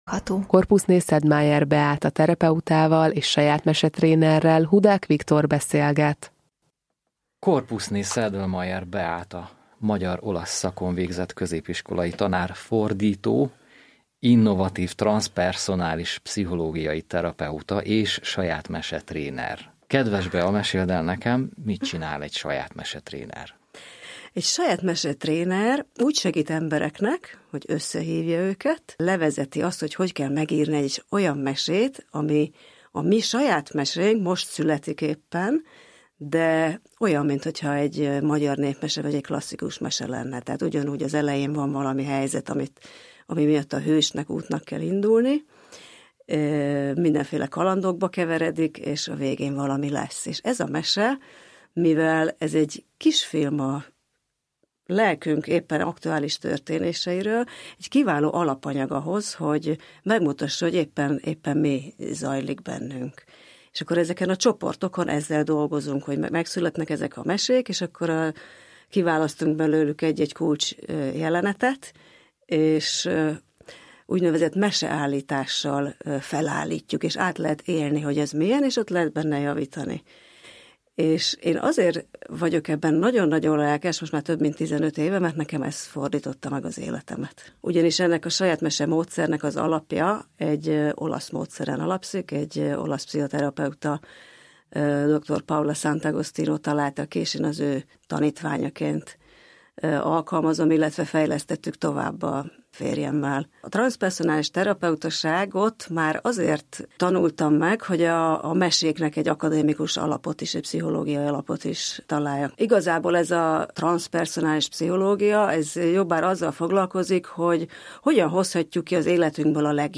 Mária Rádió interjú